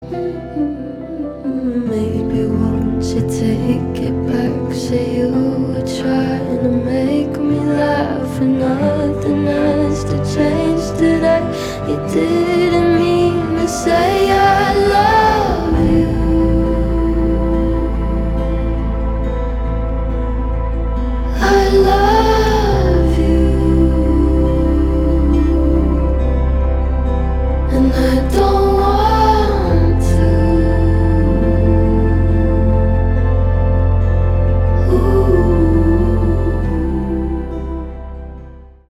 Alternative